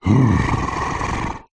Хотя его существование не доказано, эти аудиозаписи помогут вам представить его голос: от глухого рычания до странных шорохов.
Тяжелое дыхание йети